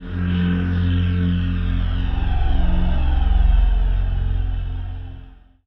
DM PAD1-28.wav